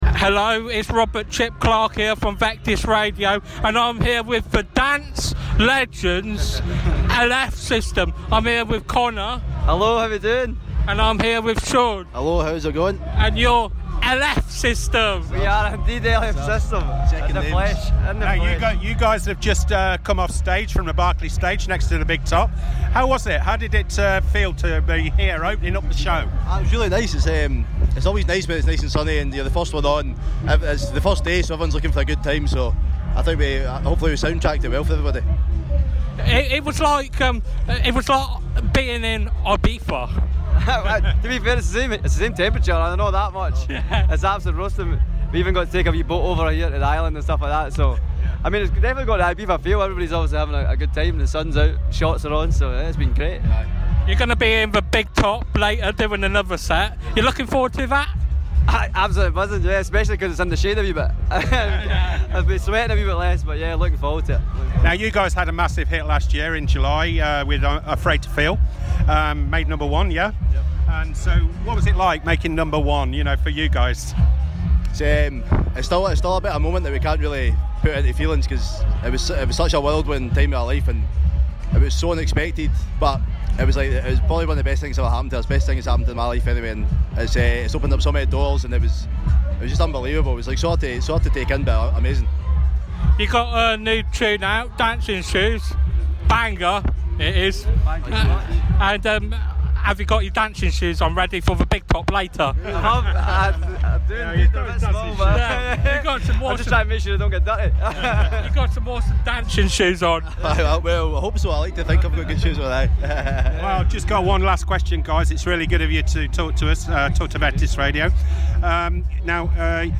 LF System Interview Isle of Wight Festival 2023